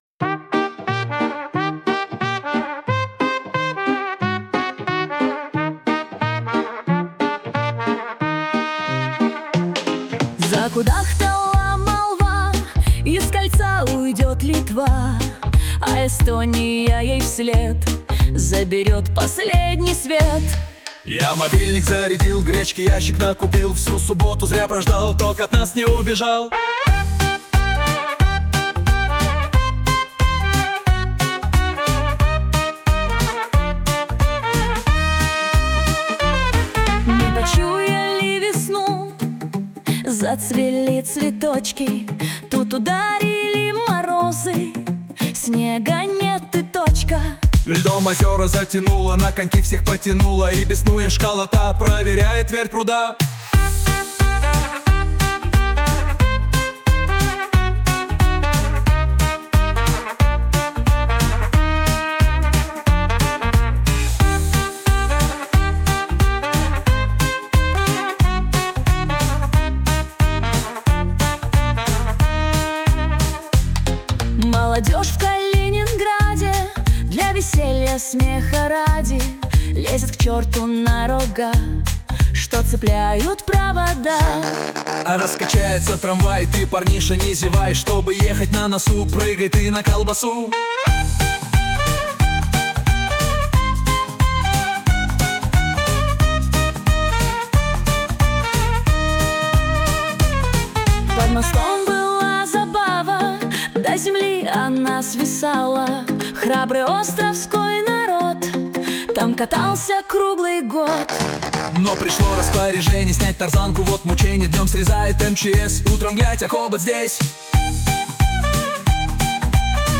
Самые заметные и важные события — в стиле джаз